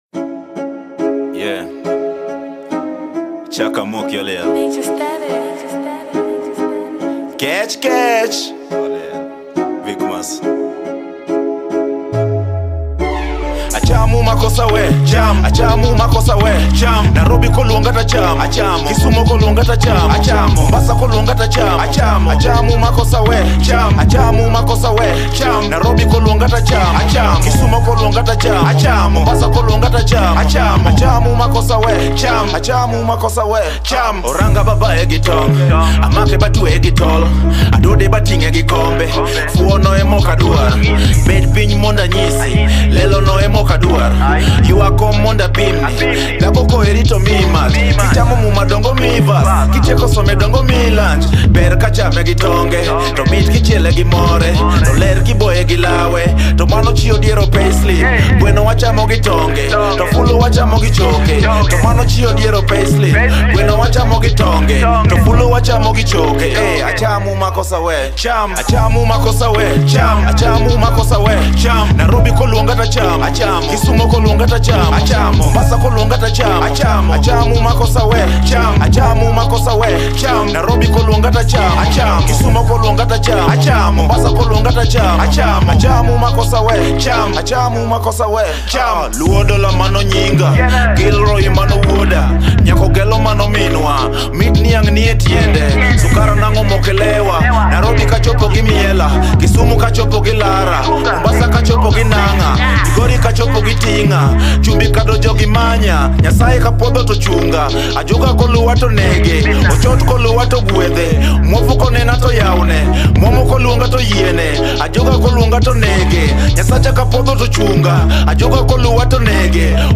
Some Trap music inspired music…